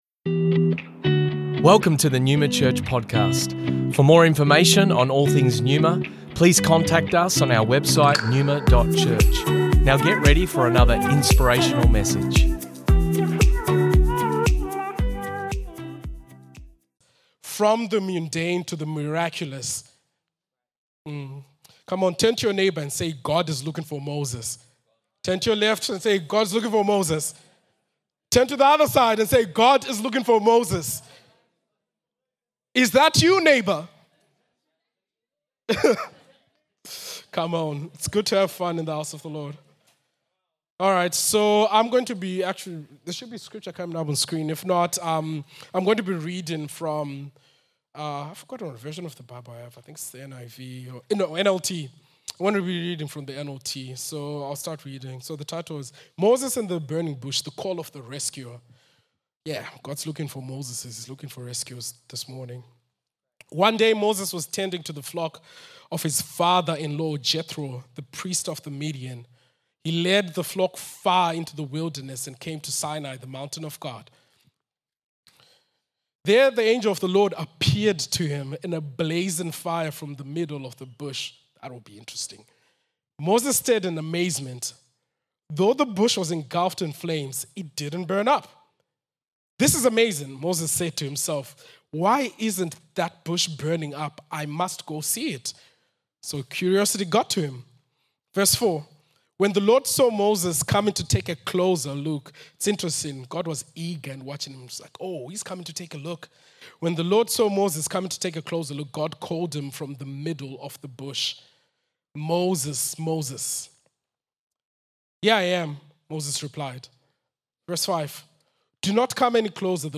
Originally recorded at Neuma Melbourne West Dec 10th 2023